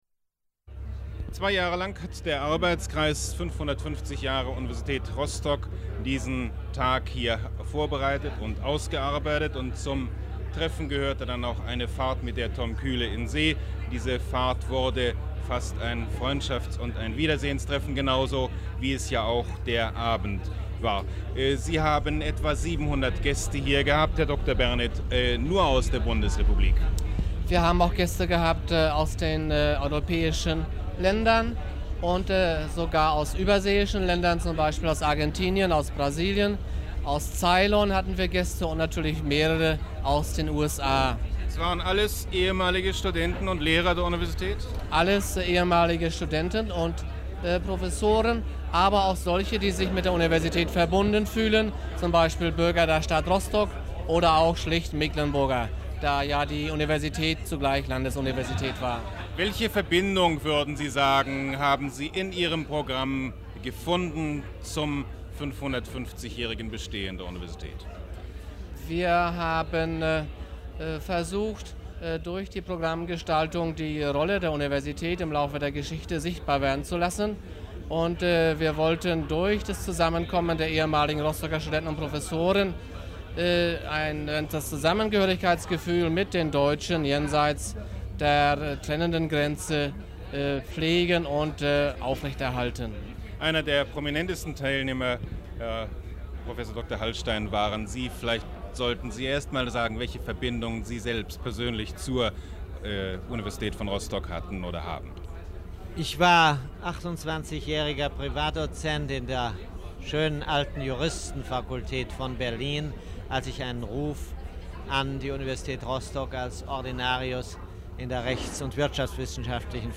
Feier des VERS in Kiel am 15.05.1969
Interview